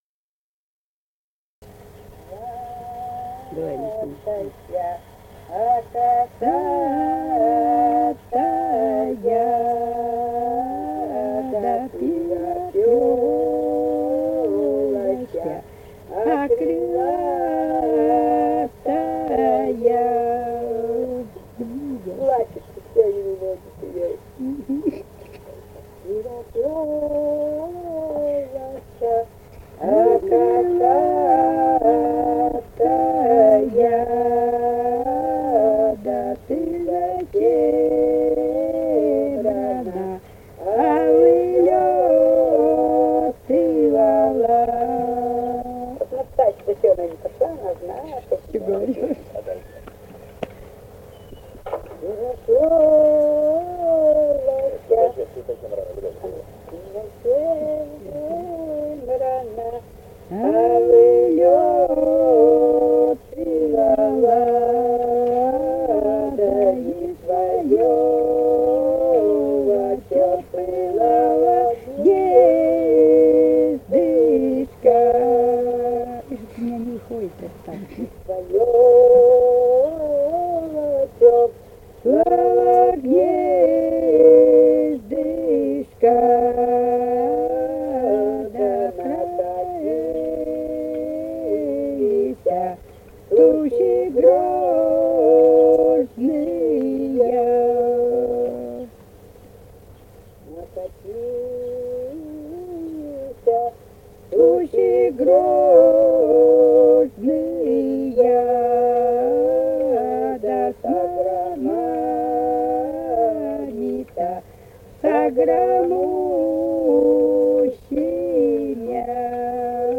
Русские песни Алтайского Беловодья 2 [[Описание файла::«Ласточка косатая», свадебная, когда невесту выводят к жениху.
в с. Печи, июль 1978.